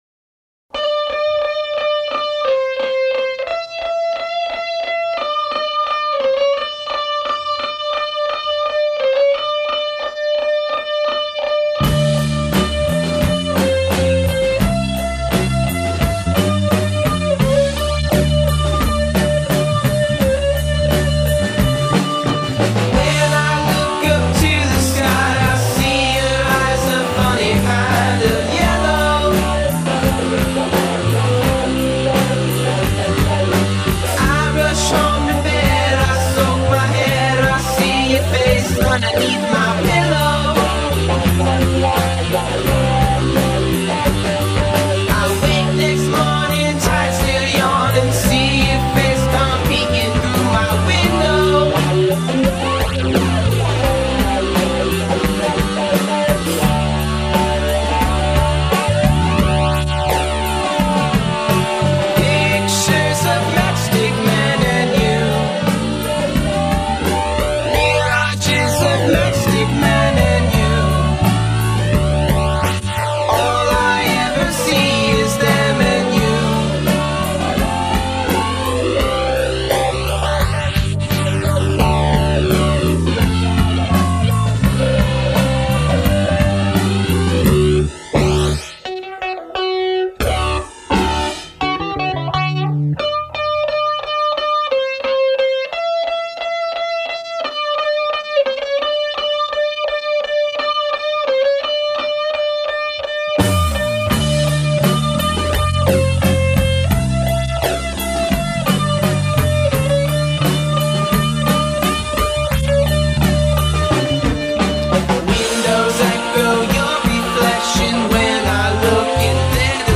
The resulting 'Flanging' effect is technically called comb filtering
Pictures-W-FlangEdits.mp3